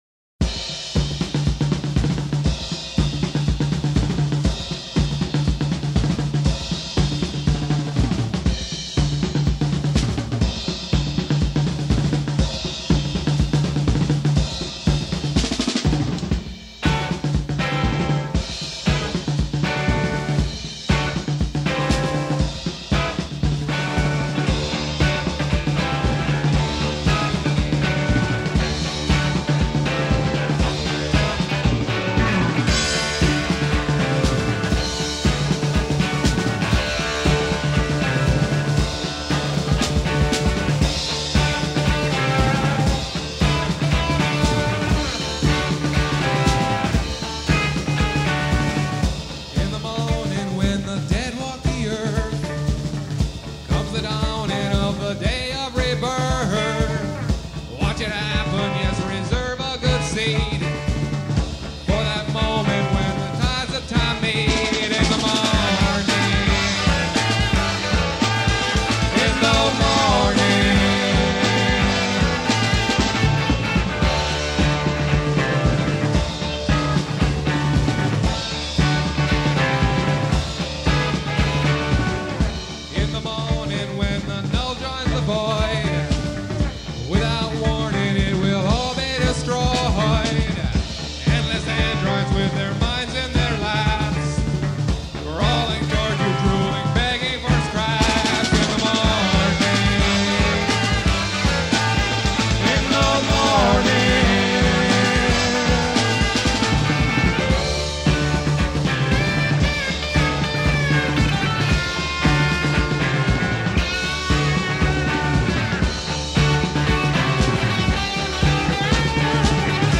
drums
lead guitar, harmony vocals
bass, harmony vocals
electric 12-string guitar, lead vocals